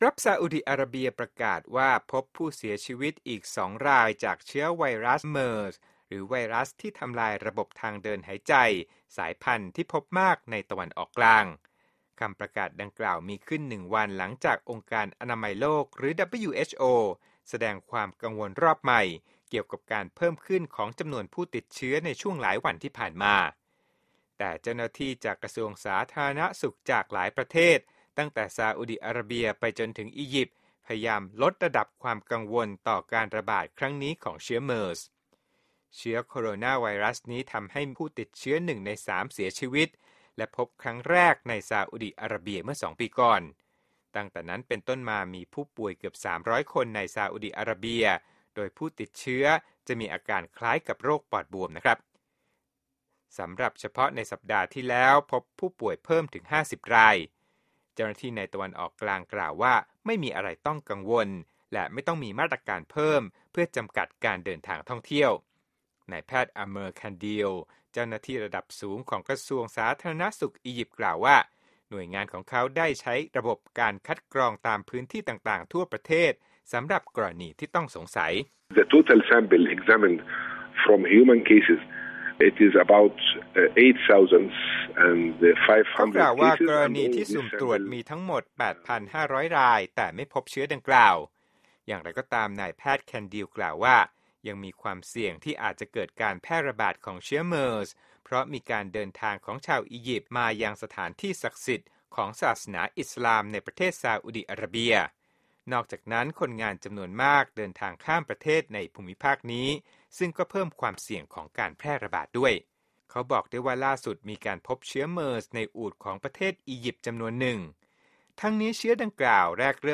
โปรดติดตามรายละเอียดจากคลิปเรื่องนี้ในรายการข่าวสดสายตรงจากวีโอเอ